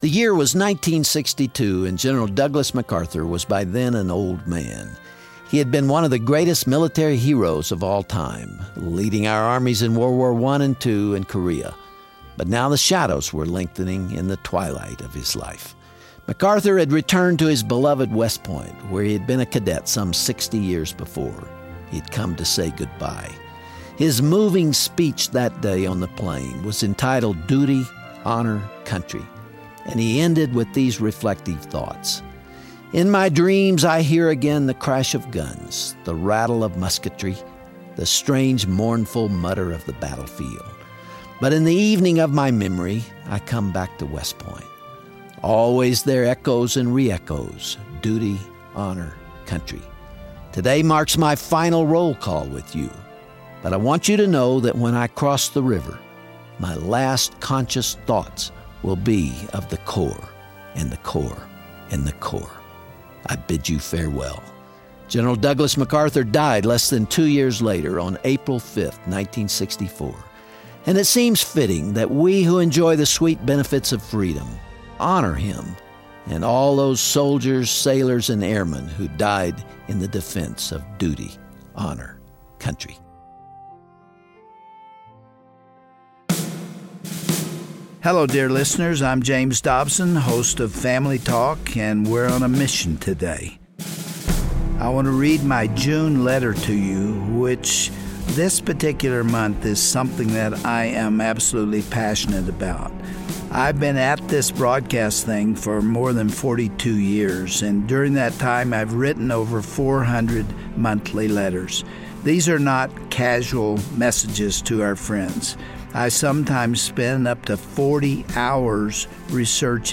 Dr. Dobson reads his June newsletter, focusing on the events of D-Day. He describes the horrific battle that claimed the lives of thousands of men, and shares his own reflective experience when he walked those hallowed beaches.